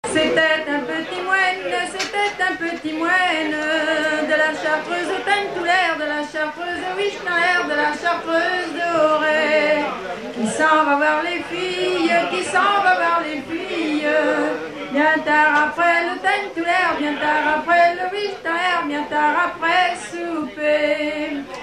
Chanson des environs de Redon
Genre laisse
Pièce musicale inédite